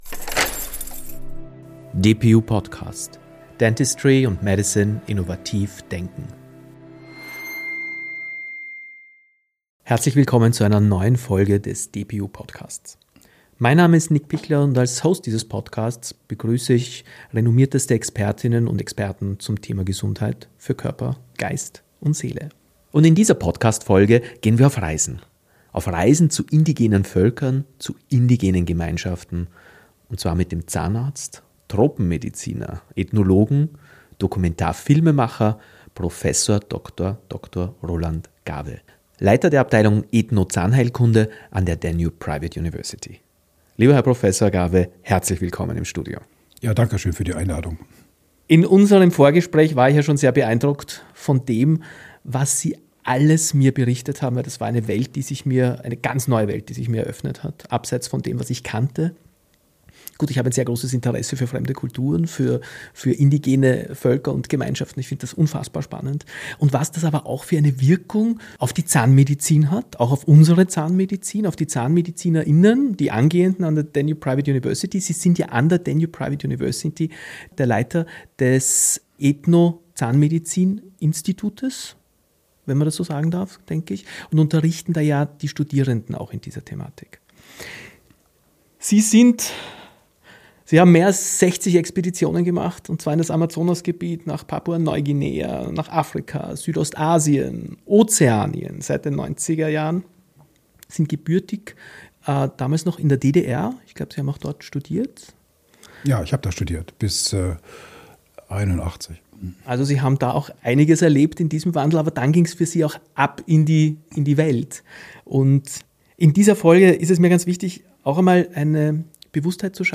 Ein Gespräch über Respekt für kulturelle Vielfalt, medizinische Verantwortung und die Frage, was wir voneinander lernen können.